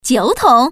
Index of /mahjong_paohuzi_Common_test/update/1658/res/sfx/putonghua/woman/